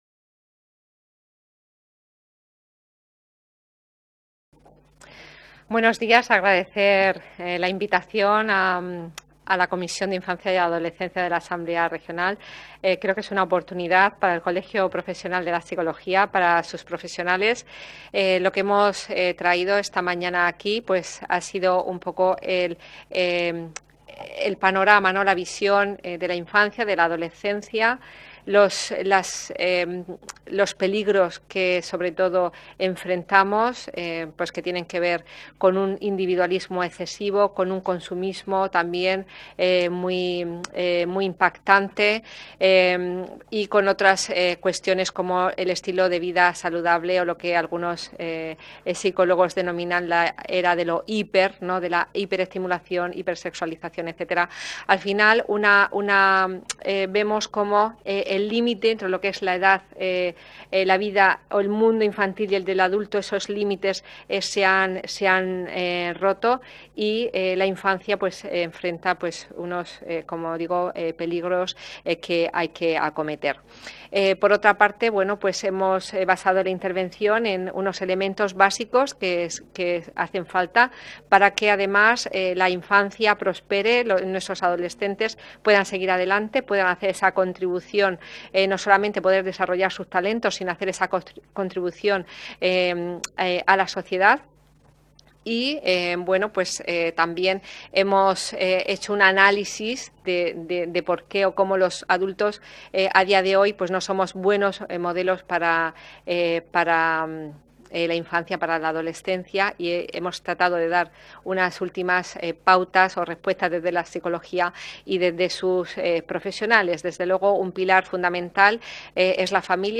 Ruedas de prensa posteriores a la Comisión Especial de Estudio sobre Infancia y Adolescencia